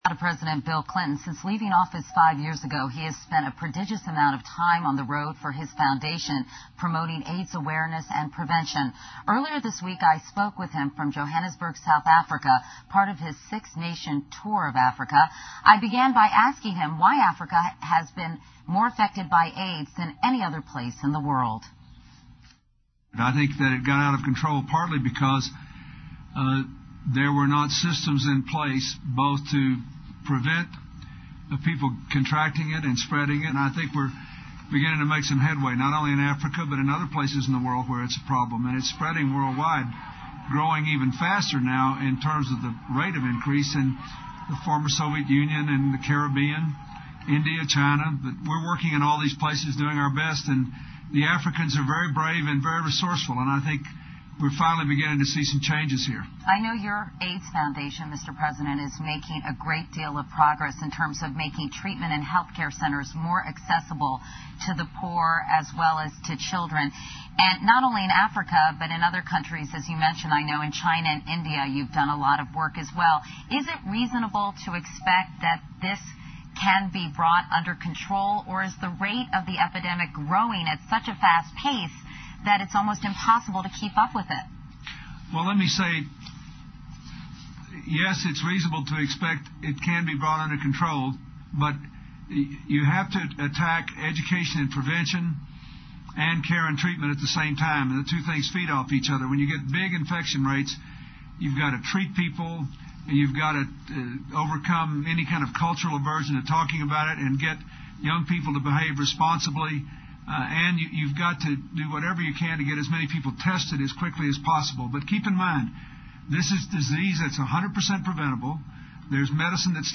克林顿专访 听力文件下载—在线英语听力室